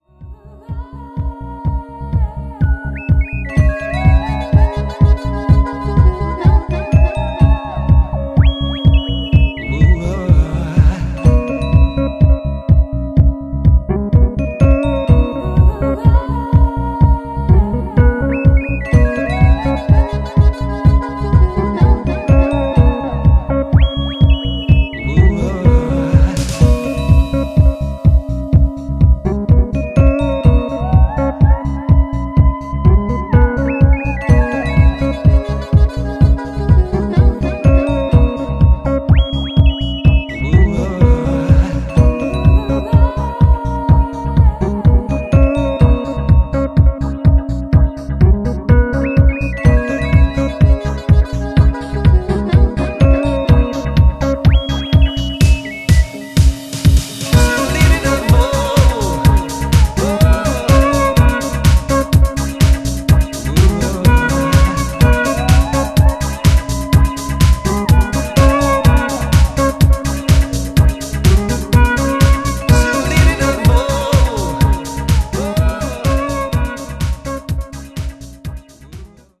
Tracks : 10 House Music Tracks